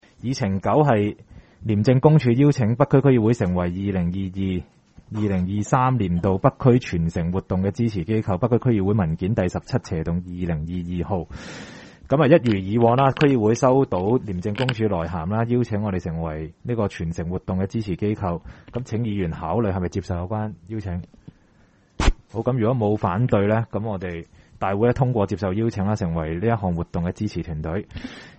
区议会大会的录音记录
北区区议会第十一次会议
北区民政事务处会议室